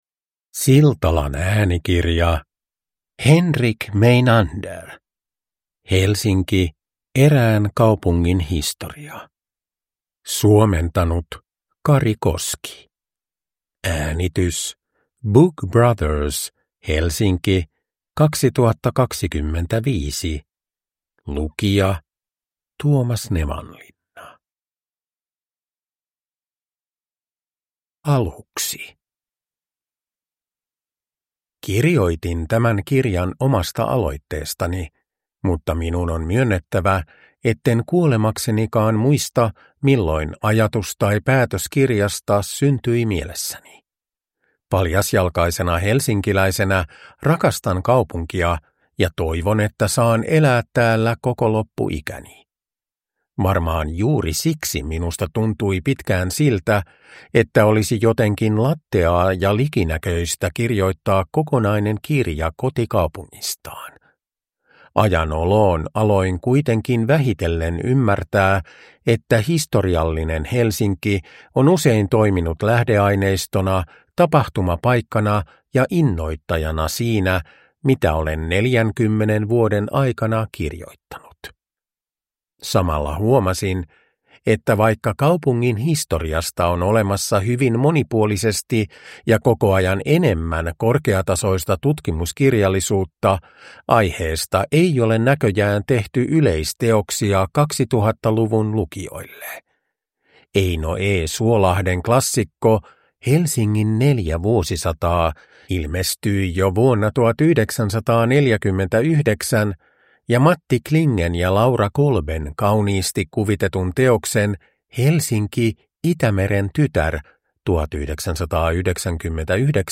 Helsinki - Erään kaupungin historia – Ljudbok
Uppläsare: Tuomas Nevanlinna